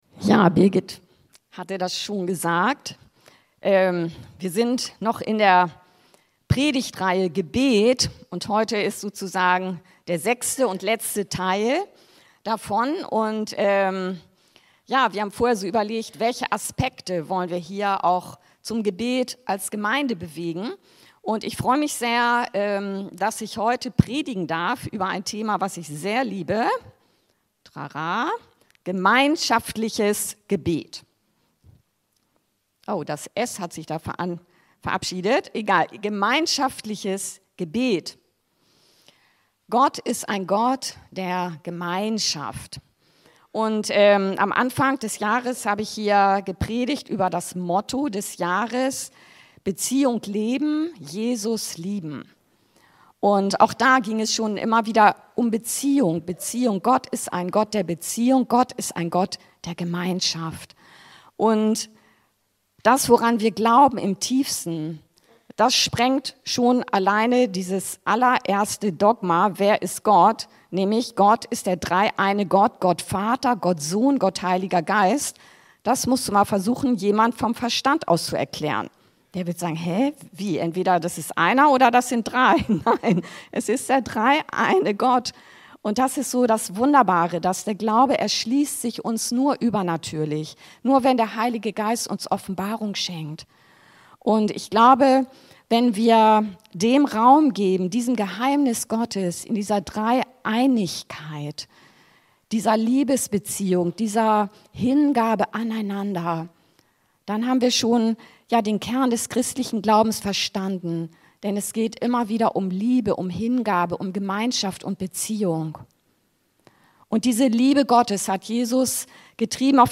Gemeinschaftliches Gebet 6. Teil der Predigtreihe ~ Anskar-Kirche Hamburg- Predigten Podcast